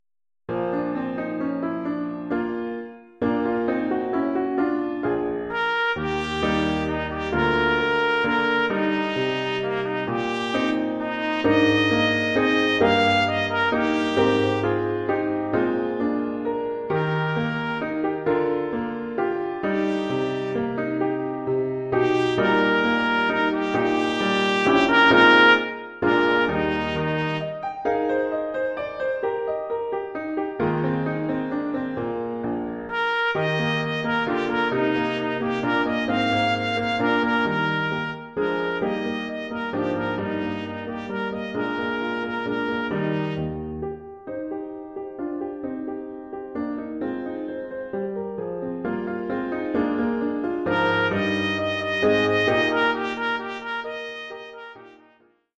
Formule instrumentale : Trompette mib et piano
Oeuvre pour trompette mib